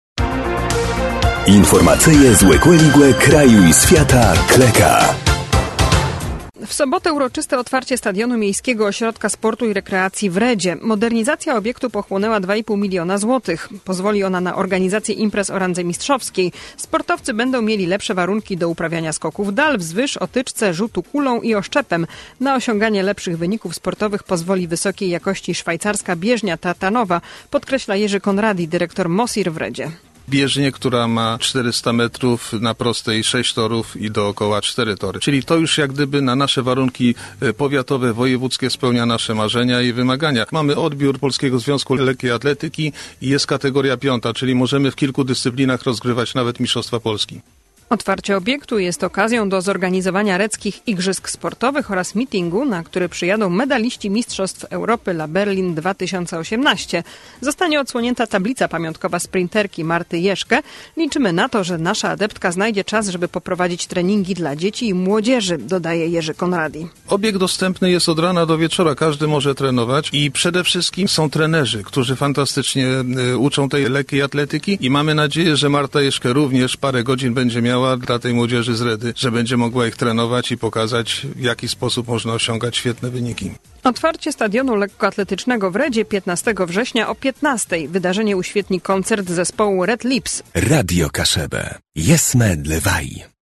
Klëka Radia Kaszëbë, 12.09.2018